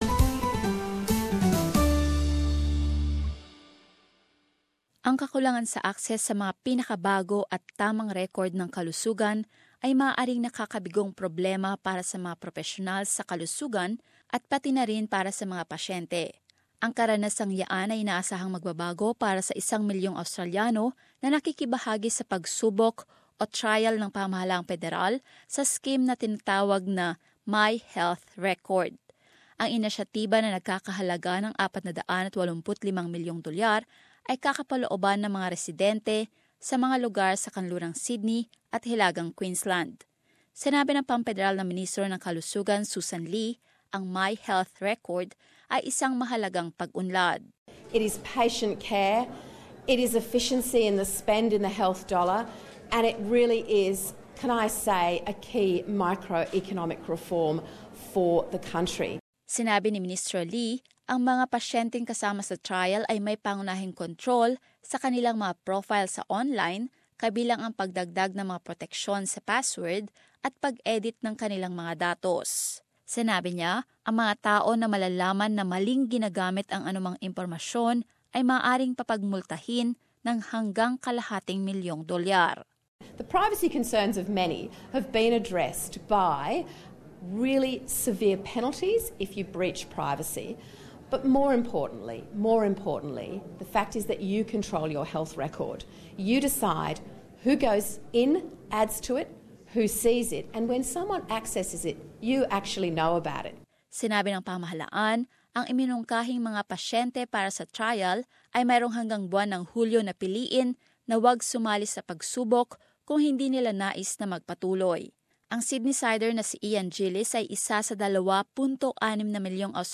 As this report shows, the government says it's rescuing what it describes as Labor's failed attempts to develop a national network of electronic health records.